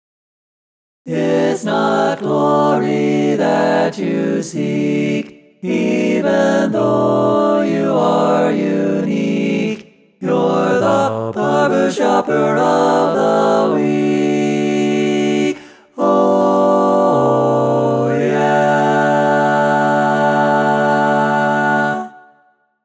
Key written in: D Major
How many parts: 4
Type: Barbershop
All Parts mix:
Learning tracks sung by